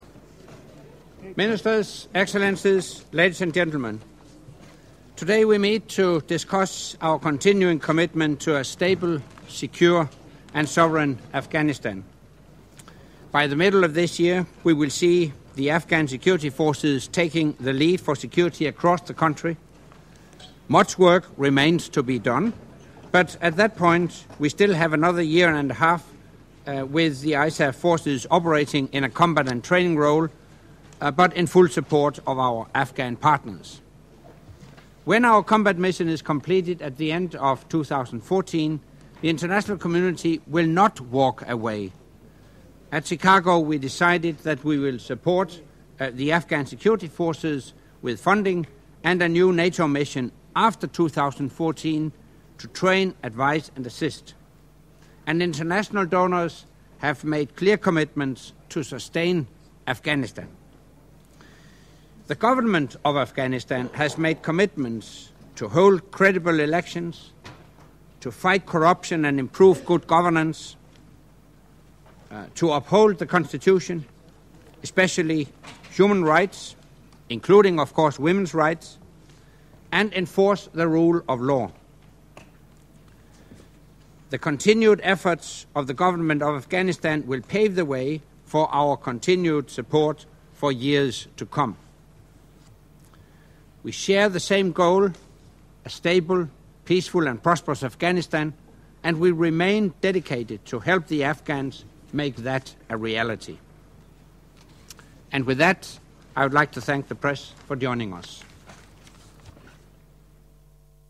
Opening remarks by NATO Secretary General Anders Fogh Rasmussen at the meeting of the NATO Ministers of Foreign Affairs with non-NATO ISAF contributing nations